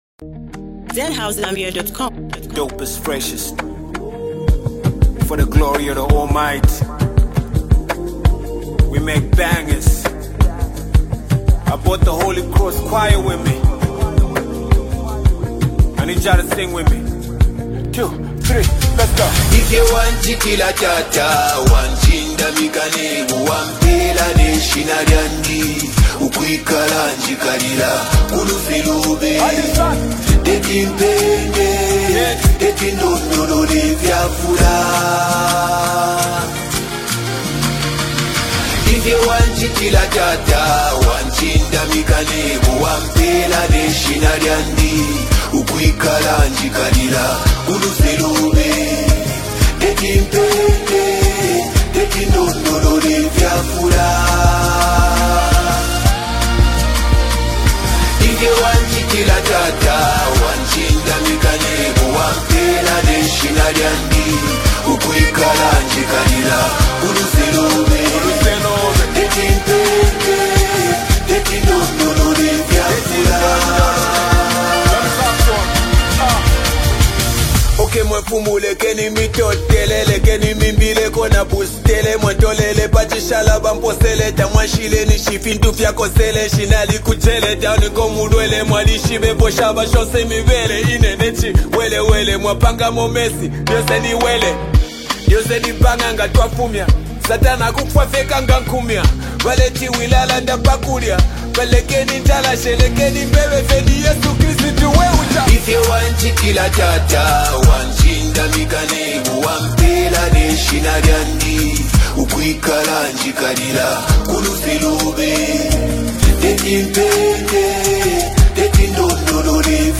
powerful vibe
With strong lyrics and a catchy beat